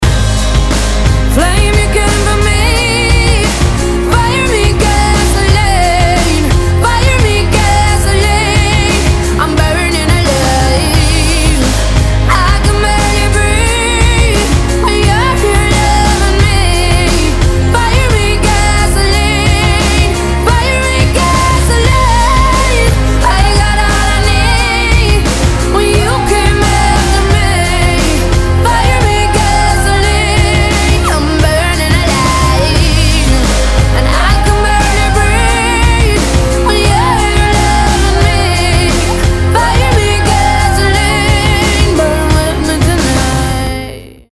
женский вокал